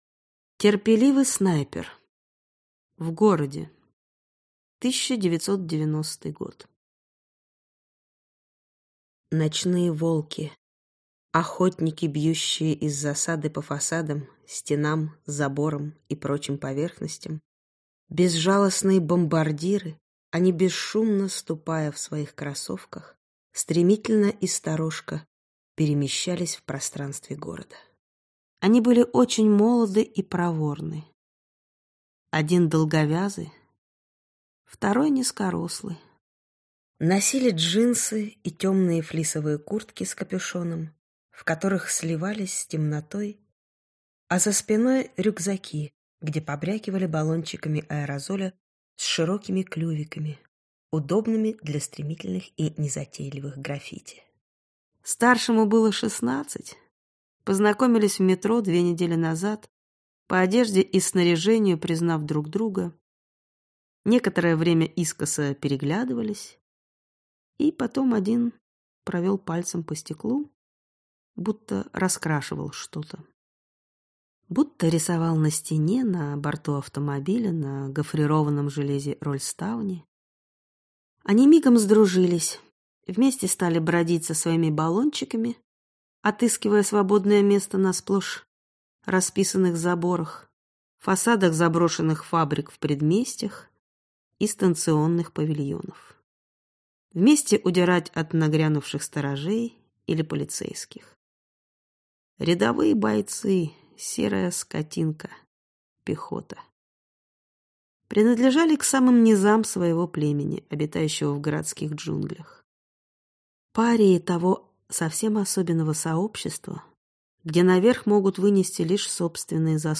Аудиокнига Терпеливый снайпер | Библиотека аудиокниг